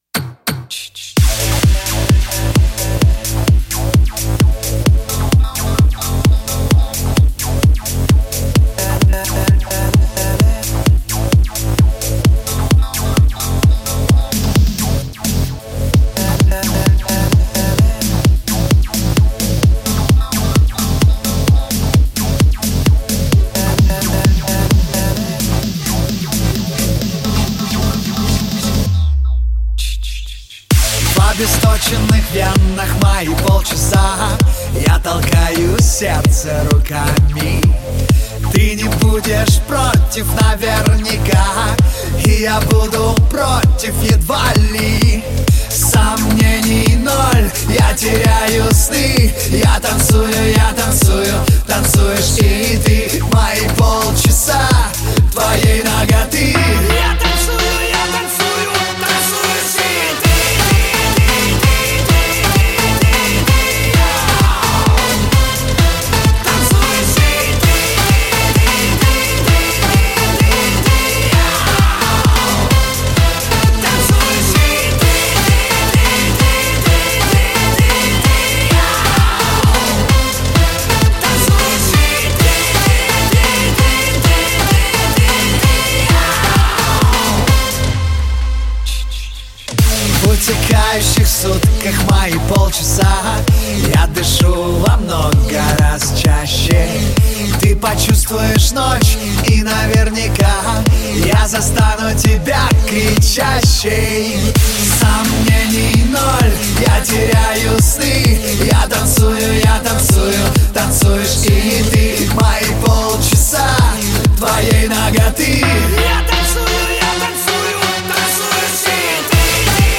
Жанр: Жанры / Альтернатива